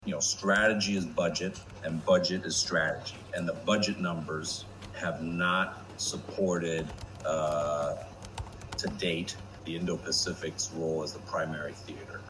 美国国防部东亚事务副助理部长科林可(Keino Klinck)说国防预算未反映印太地区作为美军主要行动区的角色。